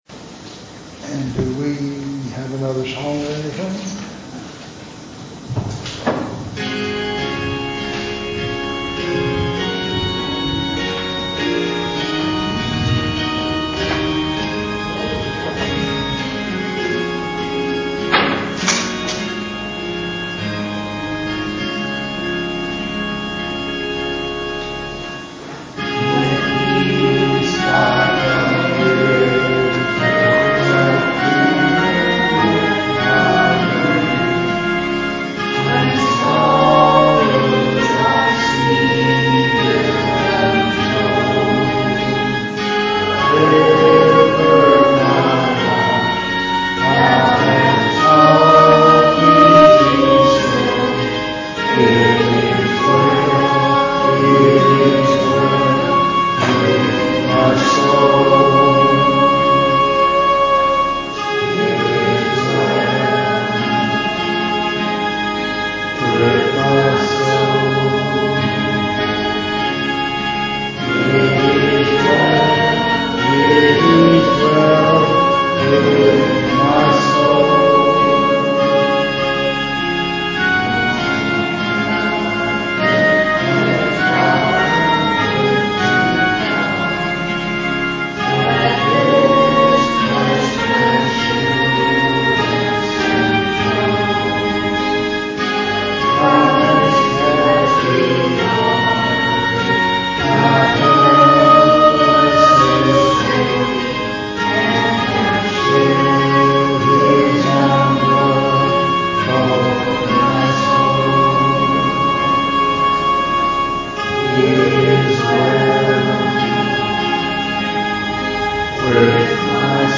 Bethel Church Service
The Message
...closing prayer